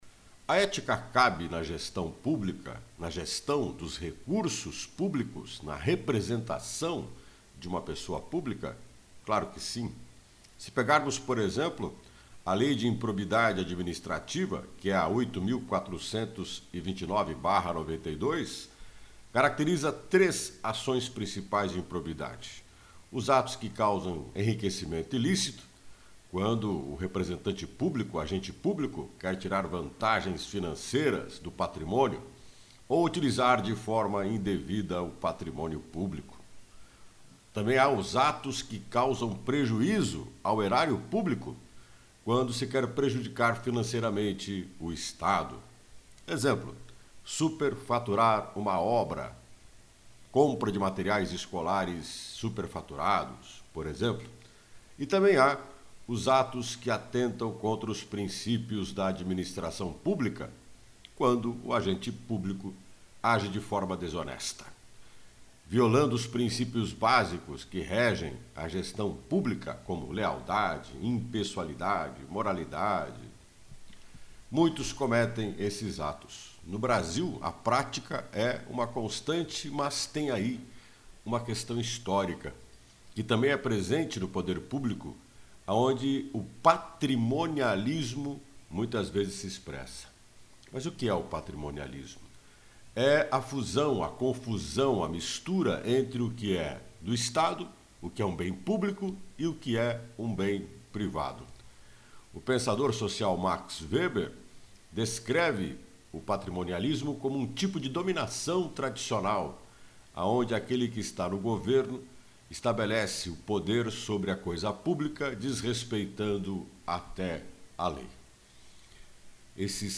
O comentário